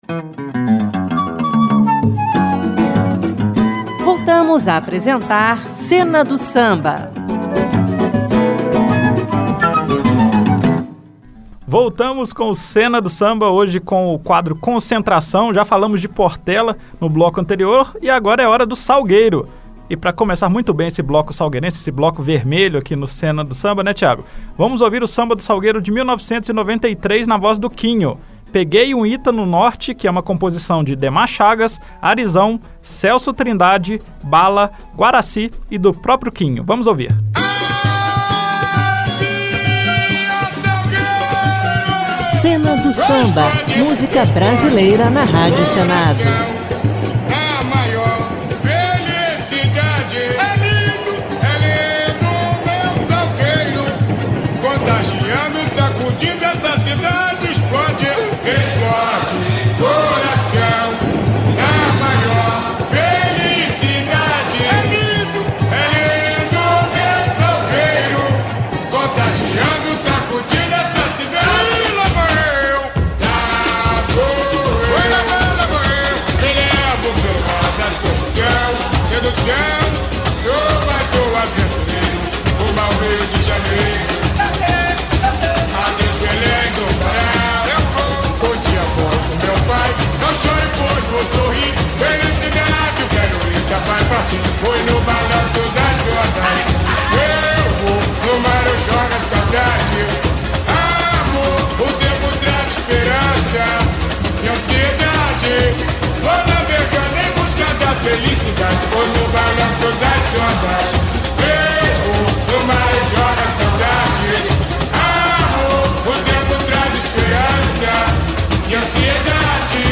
Cena do Samba entrevista intérpretes da Portela, do Salgueiro e União da Ilha. Em ritmo de carnaval, seguimos a série de entrevistas com intérpretes das escolas de samba do Grupo Especial do Rio de Janeiro.